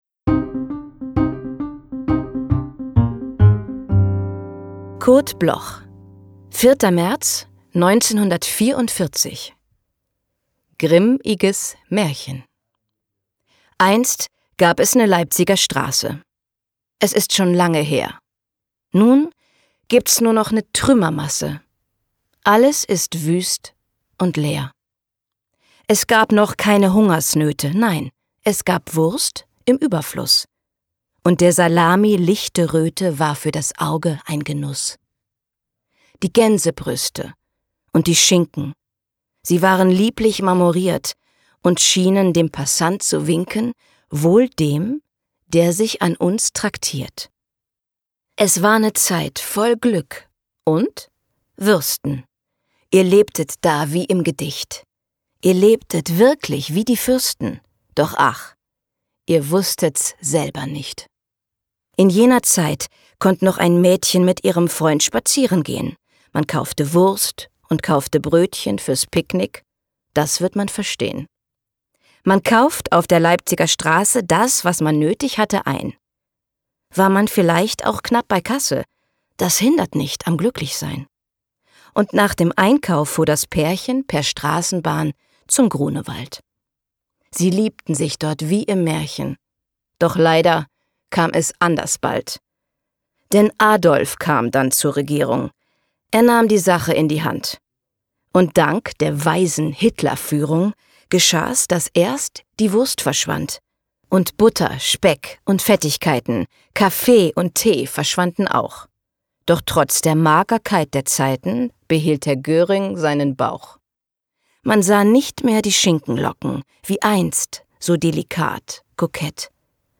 vorgetragen